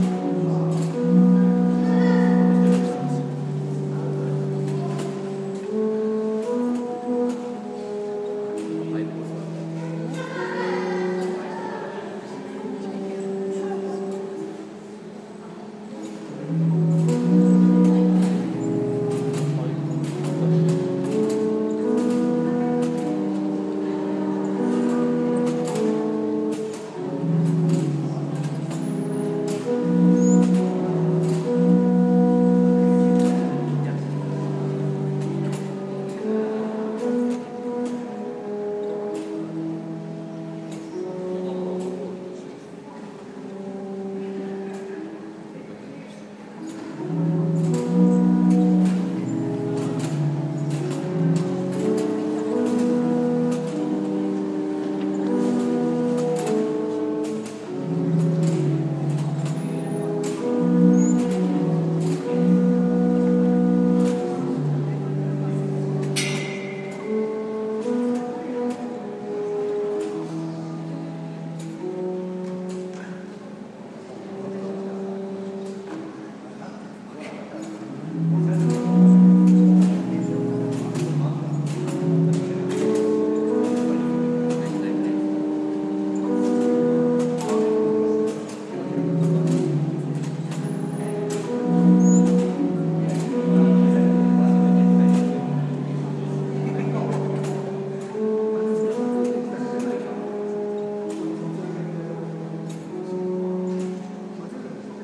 Scaffolding music